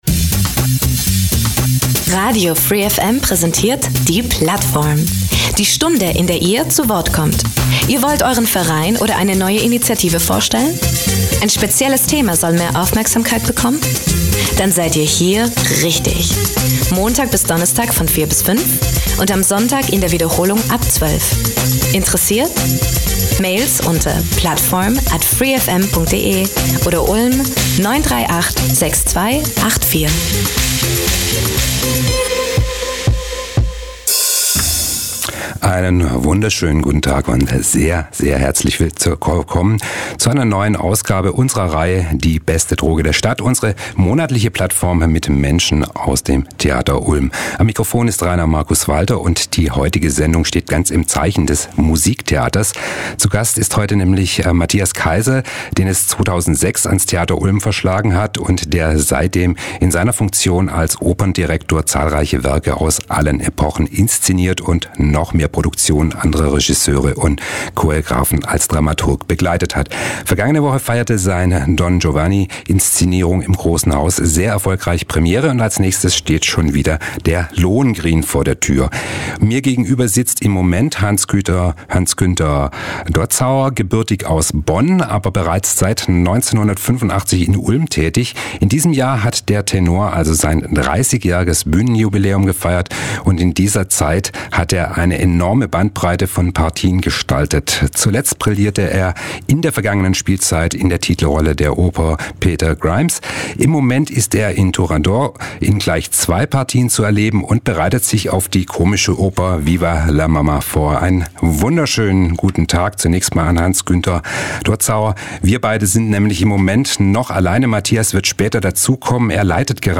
Genre Radio